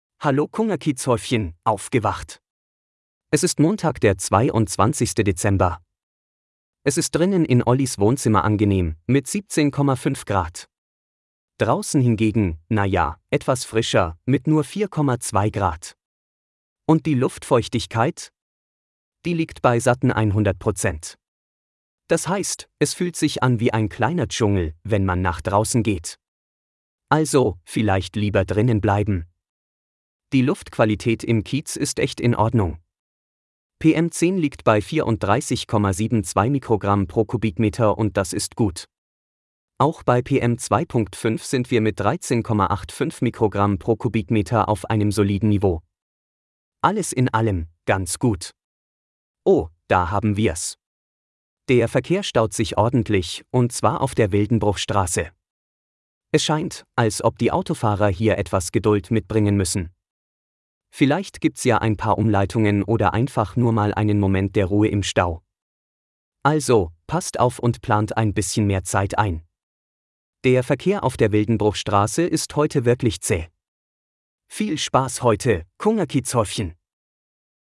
Automatisierter Podcast mit aktuellen Wetter-, Verkehrs- und Geburtstagsinfos.